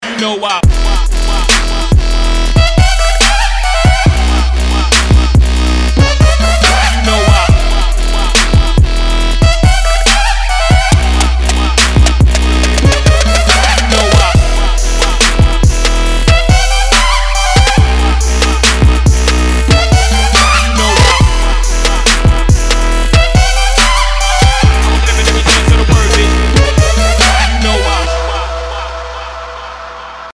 • Качество: 256, Stereo
Electronic
Trap
club
Bass
Очень крутой Трап ремикс